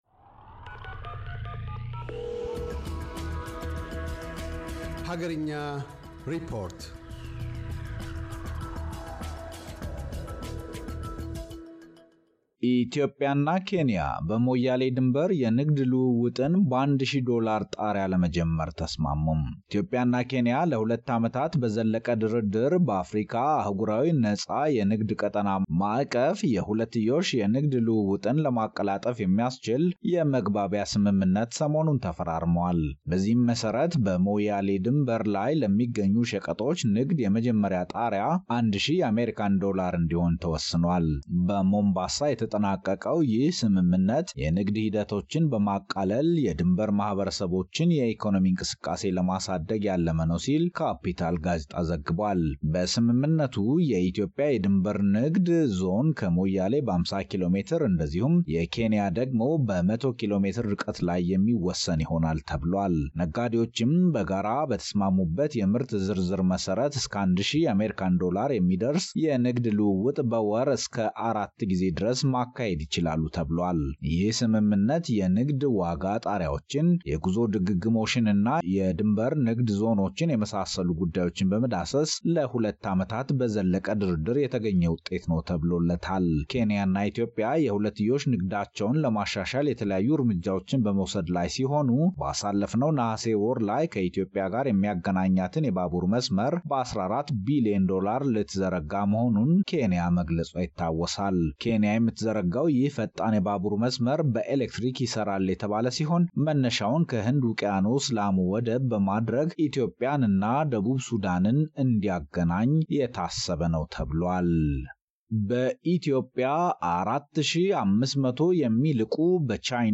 ዋና ዋና ዜናዎች